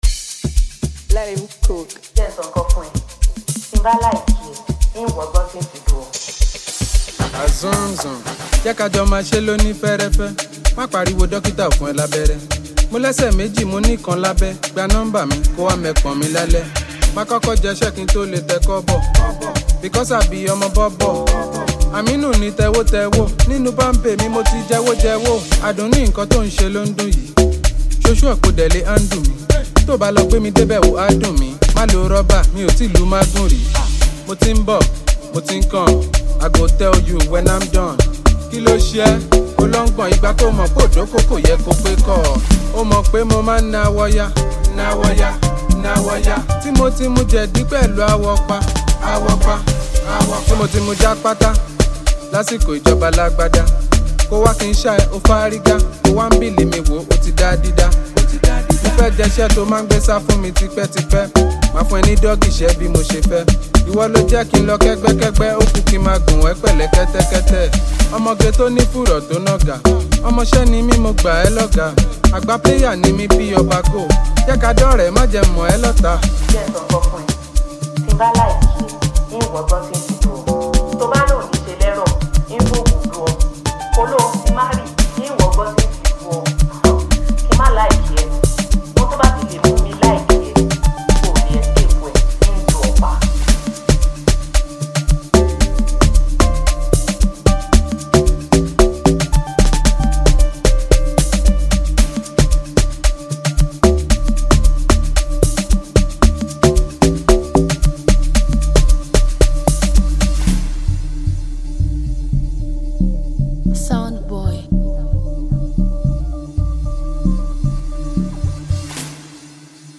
Acclaimed Nigerian singer and extraordinary musical talent